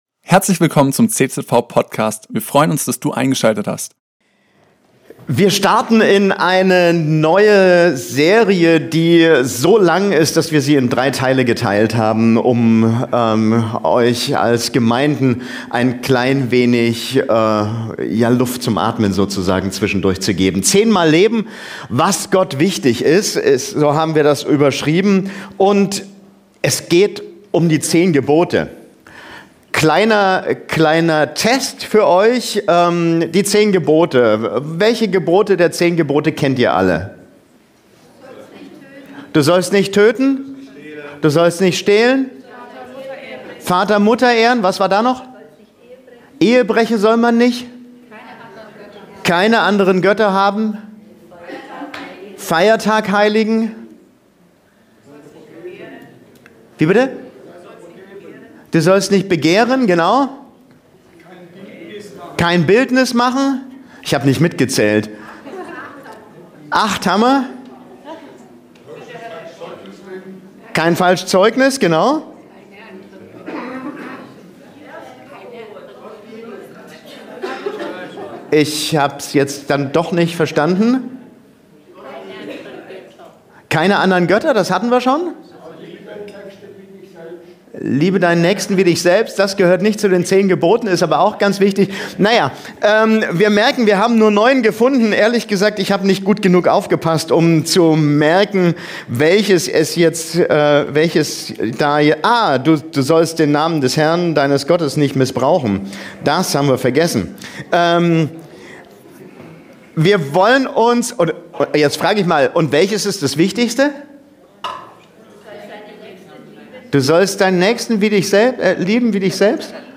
Predigtserie